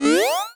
alarm_siren_loop_05.wav